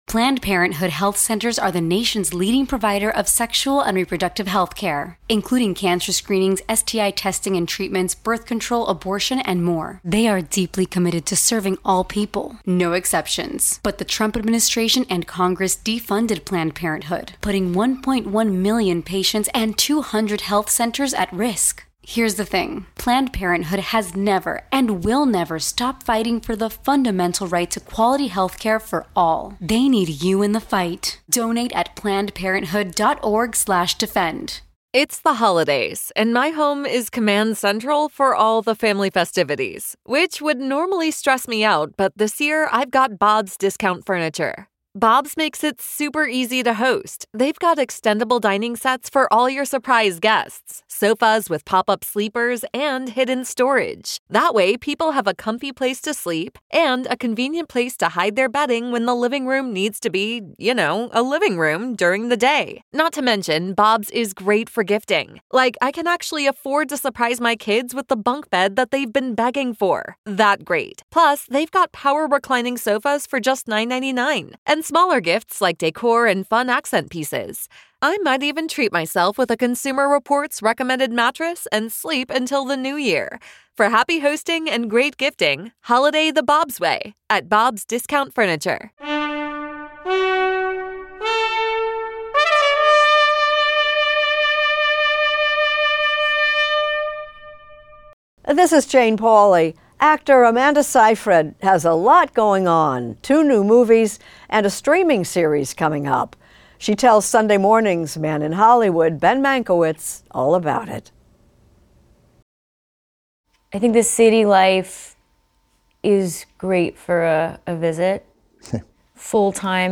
In this extended interview, the star of "Mean Girls" and "Mamma Mia!" talks with Turner Classic Movies host Ben Mankiewicz about the "emotional acrobatics" of working in films, including in "The Testament of Ann Lee", and her Oscar-nominated performance in David Fincher's "Mank". She also plays guitar and sings for us.